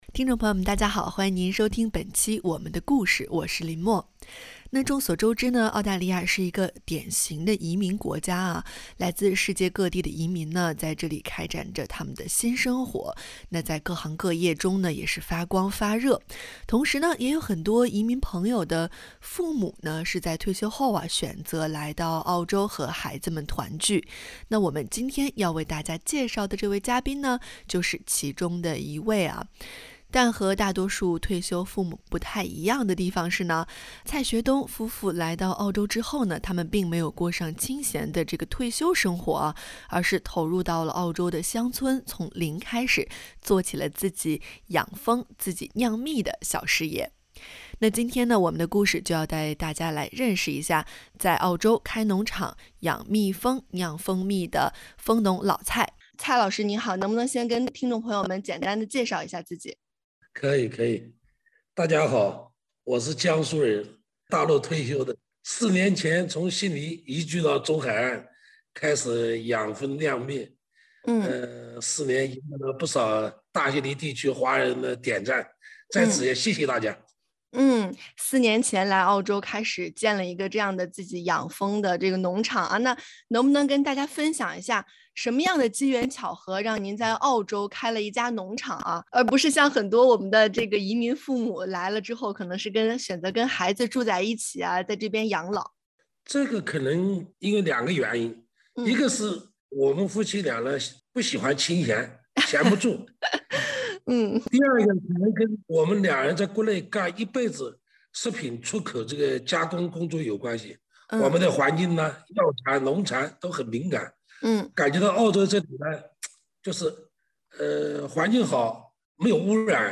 欢迎您点击封面图片，收听完整采访。